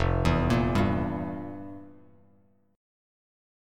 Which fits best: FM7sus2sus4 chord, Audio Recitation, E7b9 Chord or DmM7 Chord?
FM7sus2sus4 chord